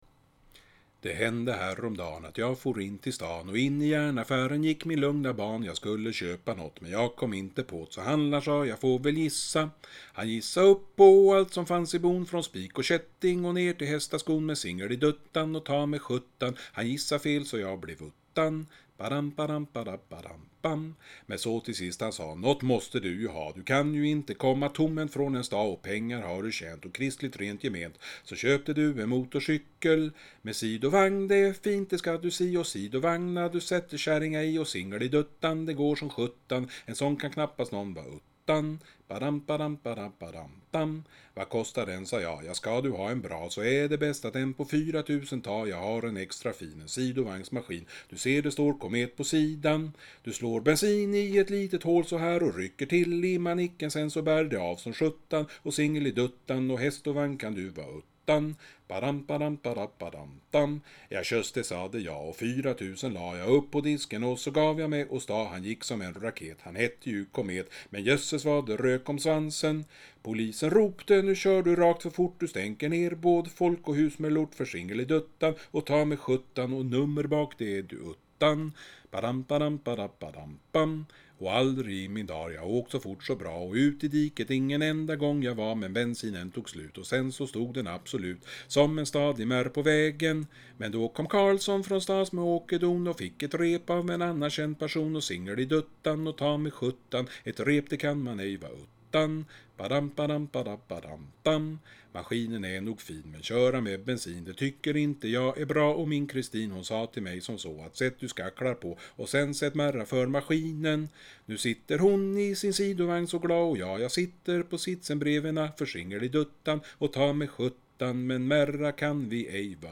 Sï¿½ hï¿½r lï¿½ter lï¿½ten (iaf nï¿½r jag sjunger den)
OBS! Inget ukuleleinnehï¿½ll. Bara sï¿½ng (eller vad man ska kalla det)